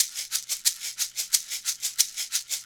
Shaker 09 PVC Pipe.wav